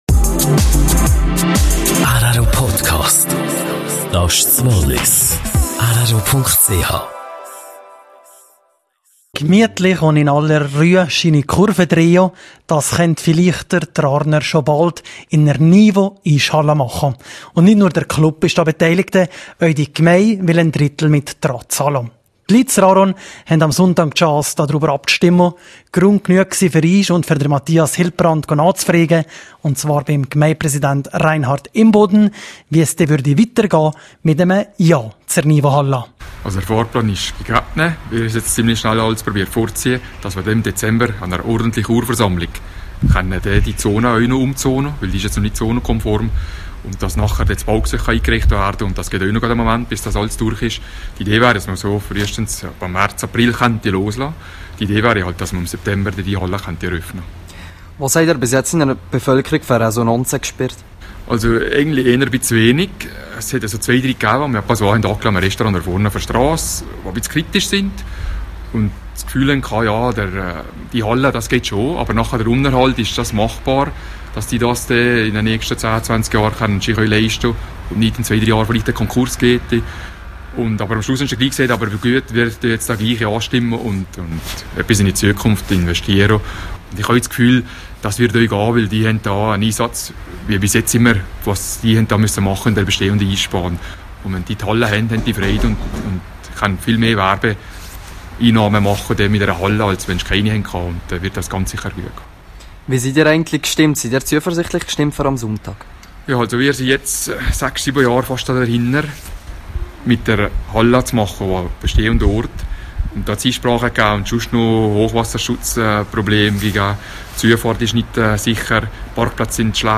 Interview mit dem Rarner Gemeindepräsidenten Reinhard Imboden über die Abstimmung am Sonntag.